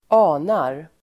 Uttal: [²'a:nar]